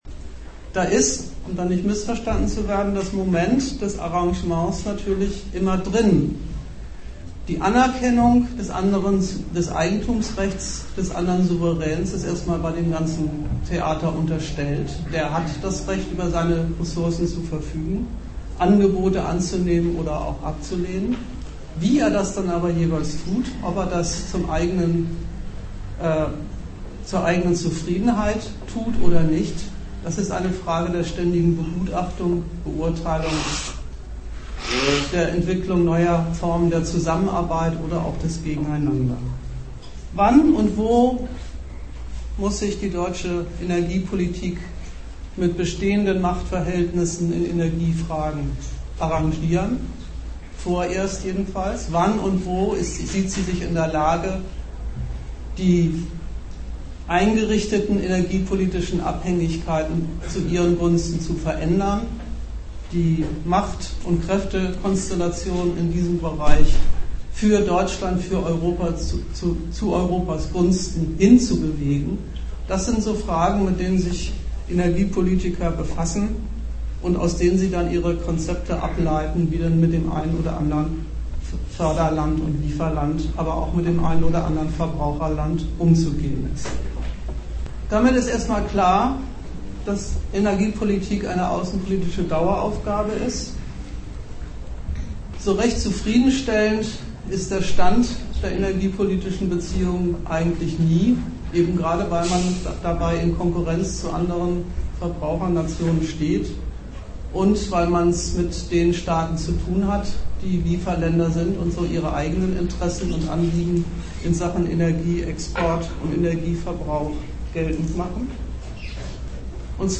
Ort Bremen
Dozent Gastreferenten der Zeitschrift GegenStandpunkt